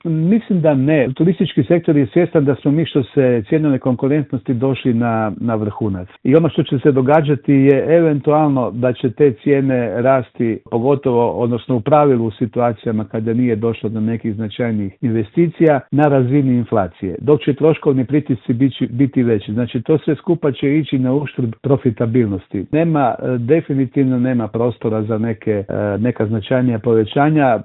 u telefonskom Intervjuu Media servisa naglašava da možemo biti optimistični, ali oprezni.